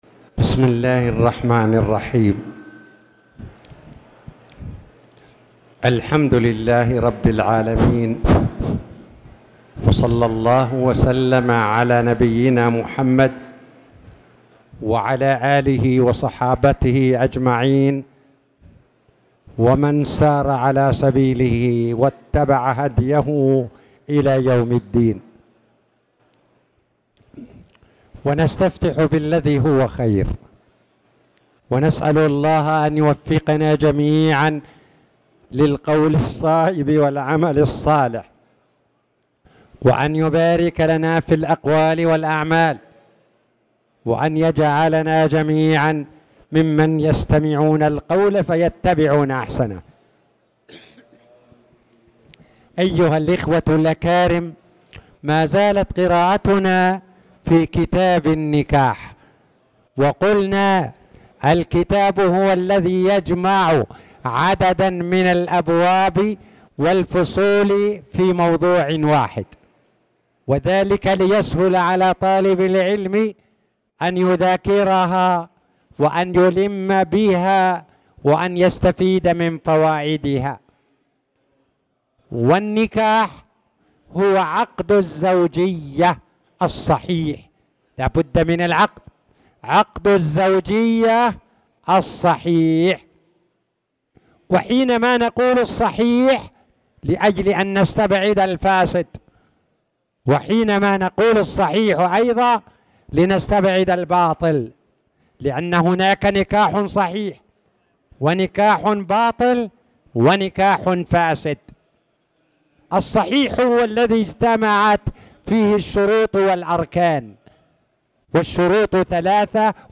تاريخ النشر ١٩ ربيع الثاني ١٤٣٨ هـ المكان: المسجد الحرام الشيخ